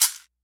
Samba Perc.wav